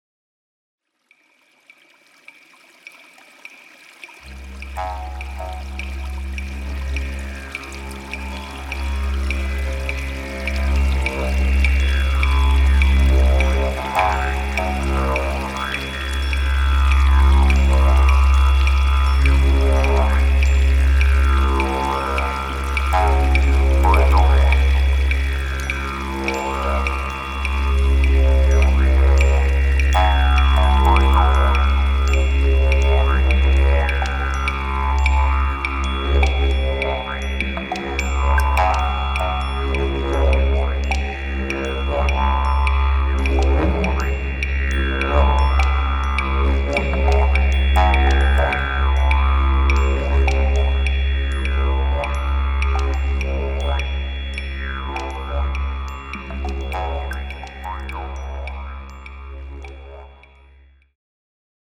Musik Klangschalen und Planetentöne